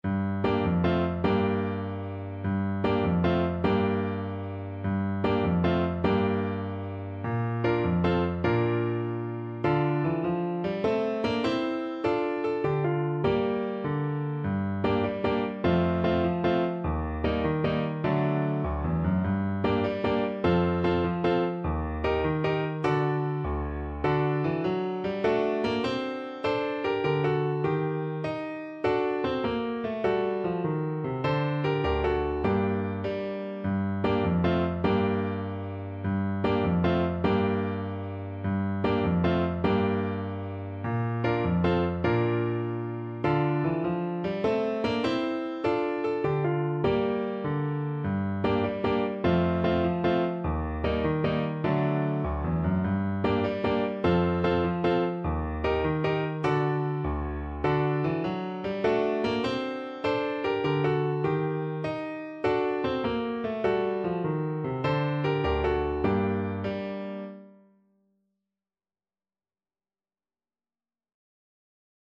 With energy .=c.100
6/8 (View more 6/8 Music)